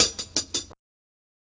Closed Hats
HIHAT BREAK 1.wav